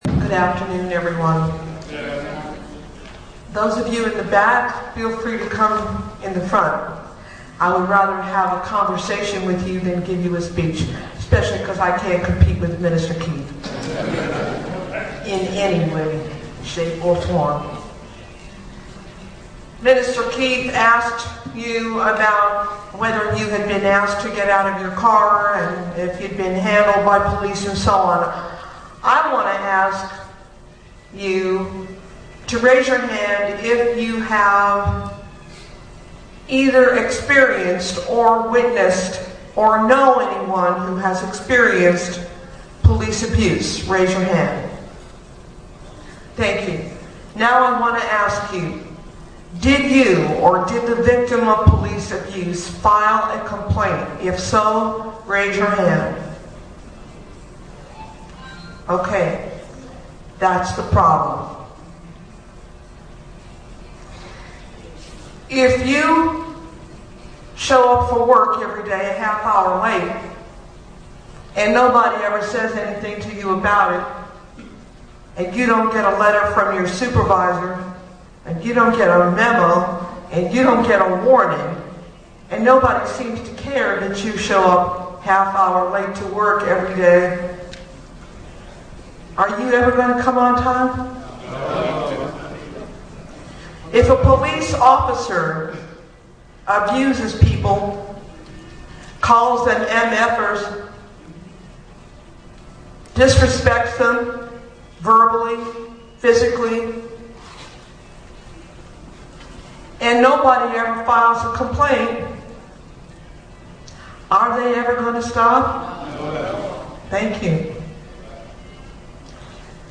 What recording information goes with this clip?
Town Hall for Justice on AB312, BART Review, and OPD Complaints, Oakland, 4/25/09: audio